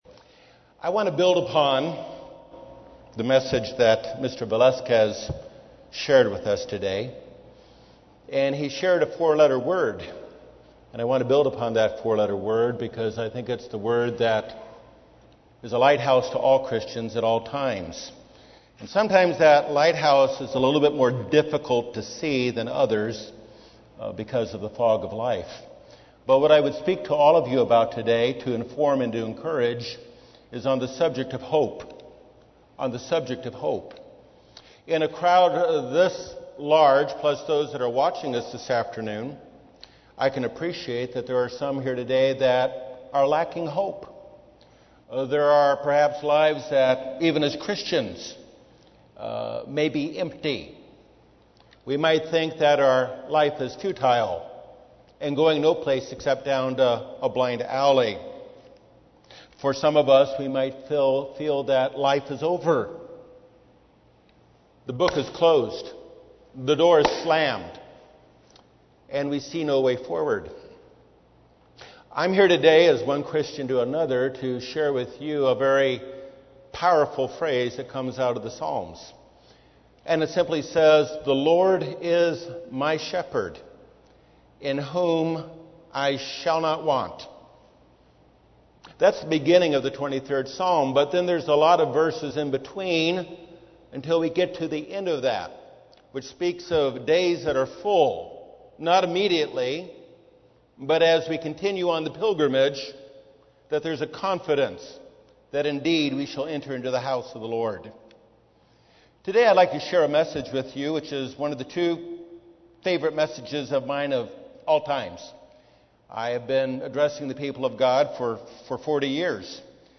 Based on the book of Ruth, this sermon delivers a message of hope and redemption for us all through Jesus Christ, our Kinsman-Redeemer.